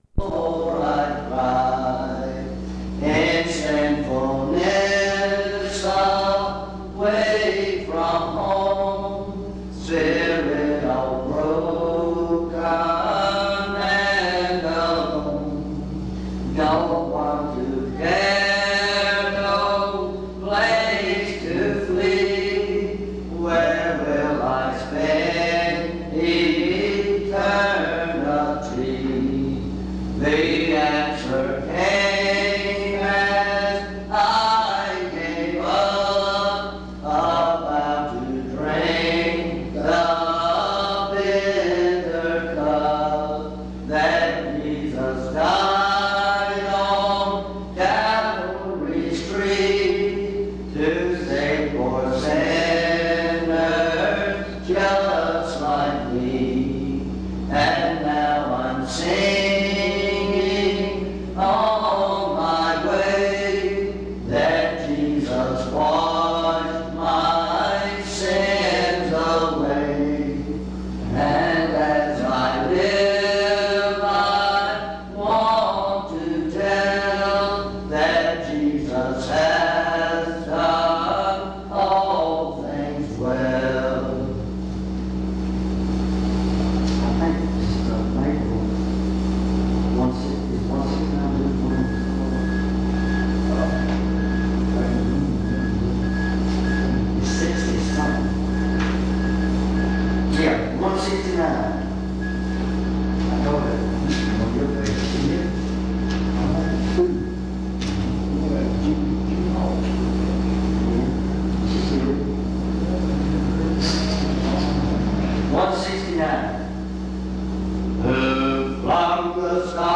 Dans Collection: Reidsville/Lindsey Street Primitive Baptist Church audio recordings La vignette Titre Date de téléchargement Visibilité actes PBHLA-ACC.001_081-A-01.wav 2026-02-12 Télécharger PBHLA-ACC.001_081-B-01.wav 2026-02-12 Télécharger